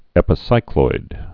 (ĕpĭ-sīkloid)